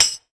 LTAMBOURIN.wav